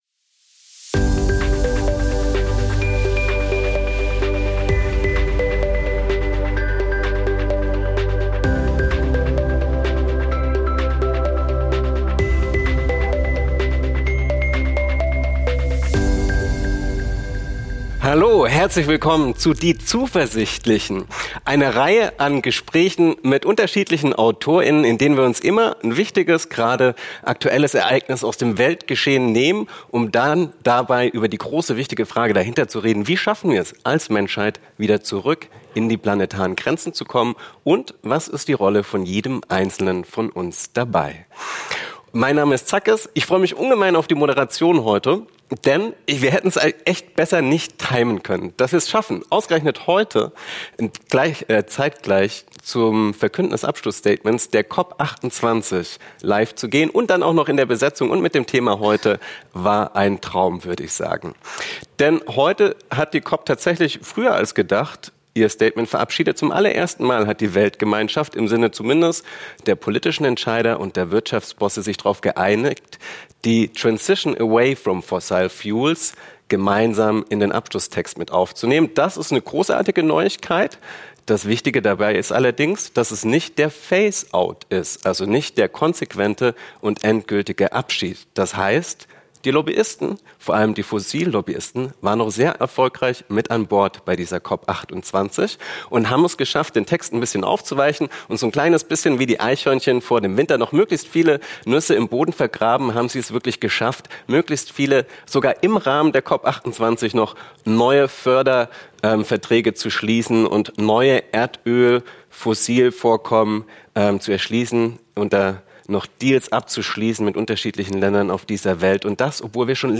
Talk